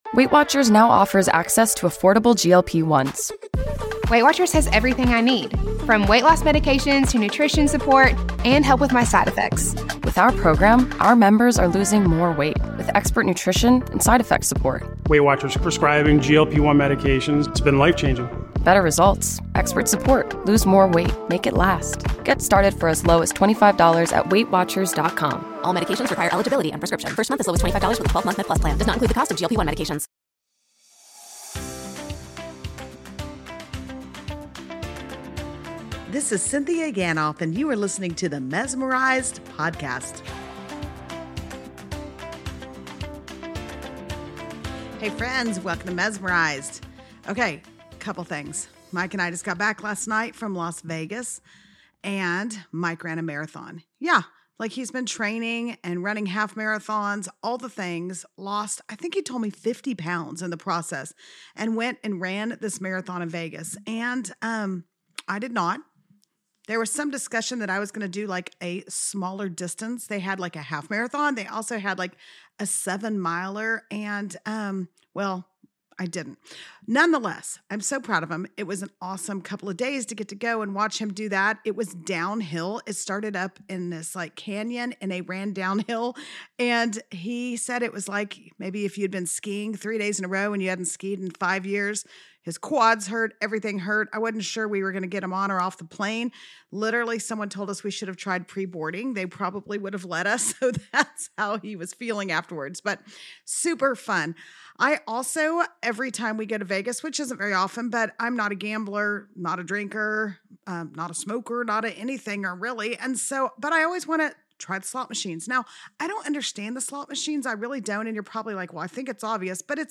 Because of travel, today we’re re-releasing one of my favorite conversations with the GOAT himself, Max Lucado.